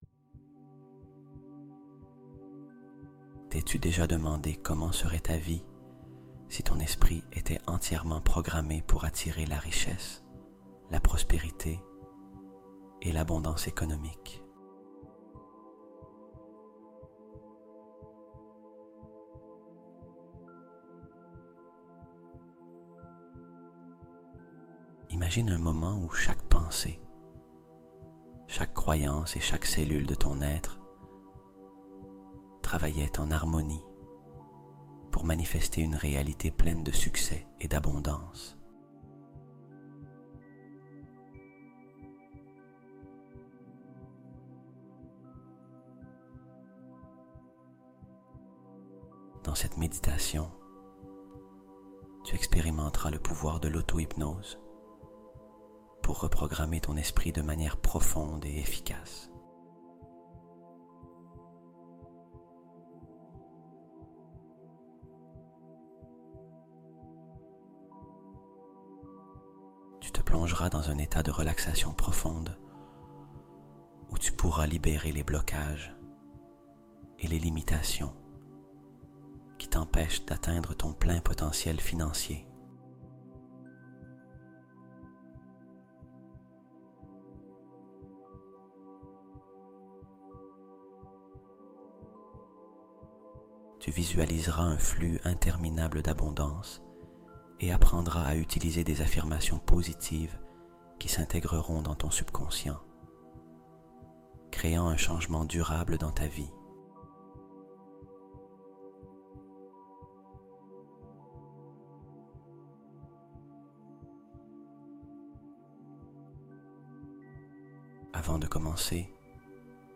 L'Auto-Hypnose La PLUS PUISSANTE Pour Devenir Riche : Prospérité et Abondance Garanties